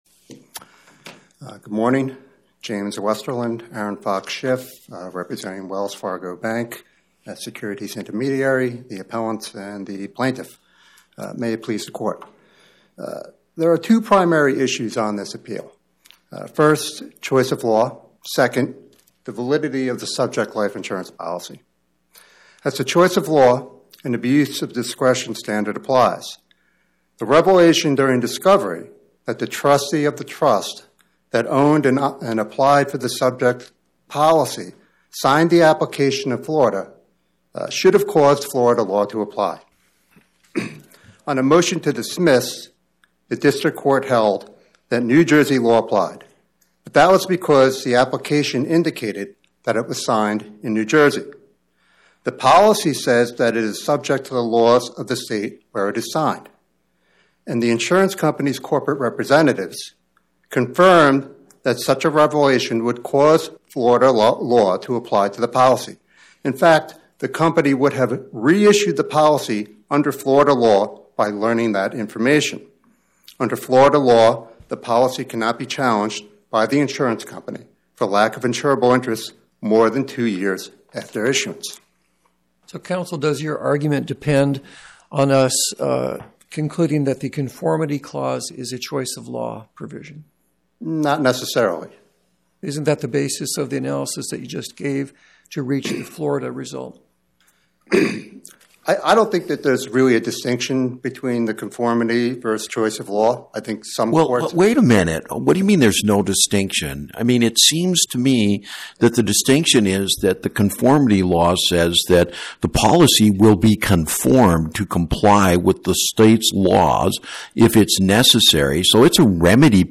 My Sentiment & Notes 25-2351: Wells Fargo Bank N.A. vs Ameritas Life Insurance Corp. Podcast: Oral Arguments from the Eighth Circuit U.S. Court of Appeals Published On: Tue Mar 17 2026 Description: Oral argument argued before the Eighth Circuit U.S. Court of Appeals on or about 03/17/2026